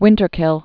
(wĭntər-kĭl)